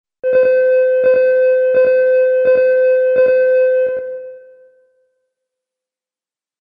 BELL 04
1980s-1990s Australia Bell Melbourne School Tone Vintage sound effect free sound royalty free Memes